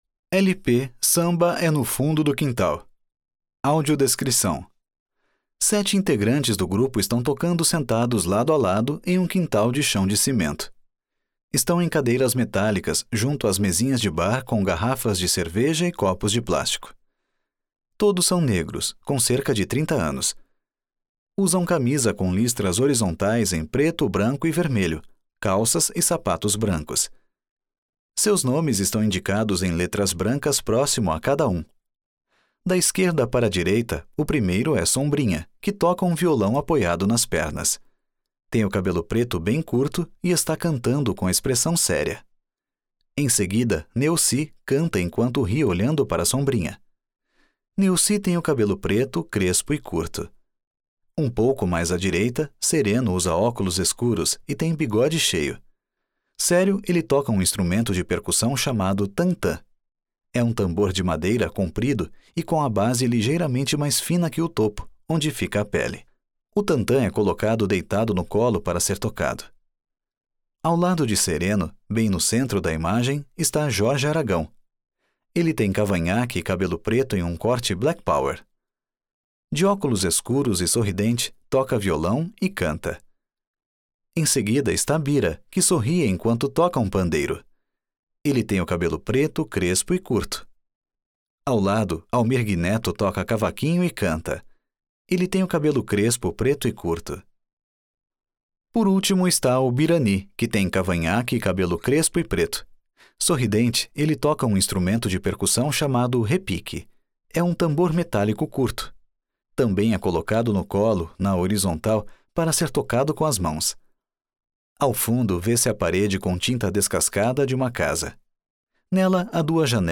Pequenas Áfricas - Audiodescrição | Estação 18 - Instituto Moreira Salles